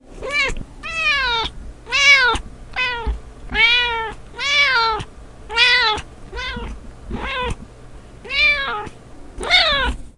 猫咪喵喵
描述：一只猫惊讶地喵喵叫。
标签： 动物 喵喵叫
声道立体声